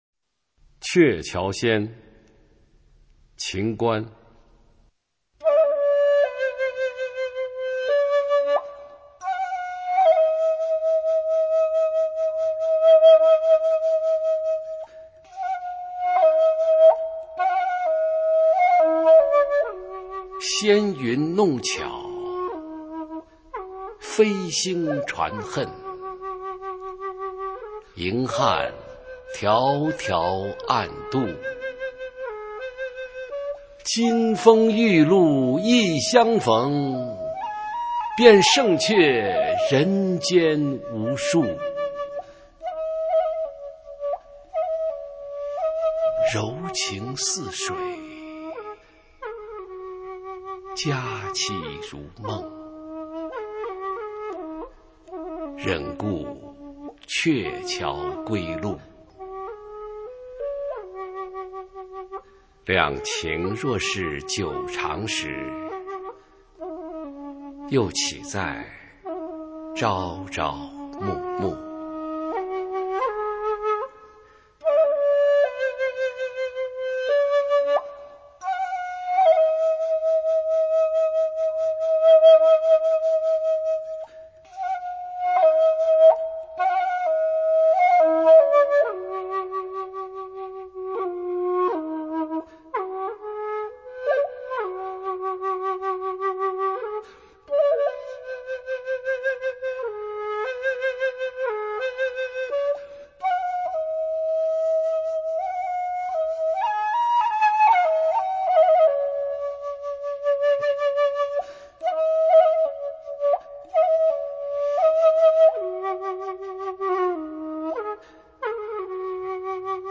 普通话美声欣赏：鹊桥仙